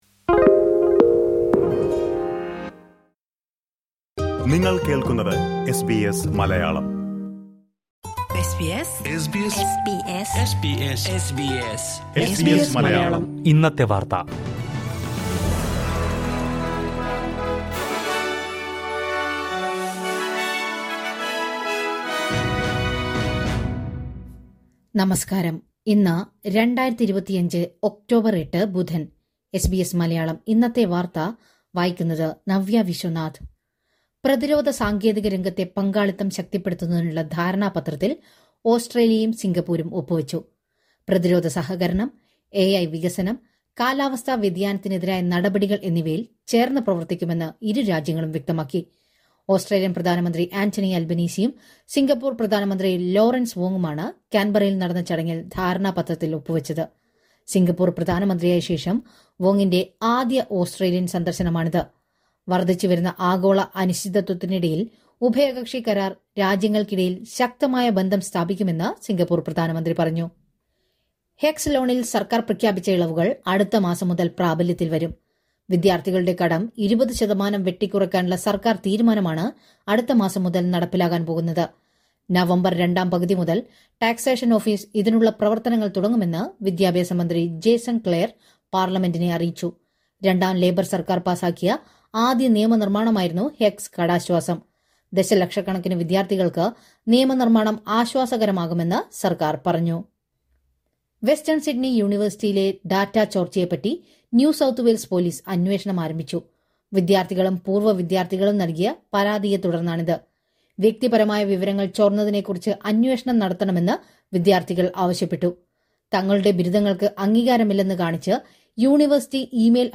2025 ഒക്ടോബർ 8ലെ ഓസ്ട്രേലിയയിലെ ഏറ്റവും പ്രധാന വാർത്തകൾ കേൾക്കാം...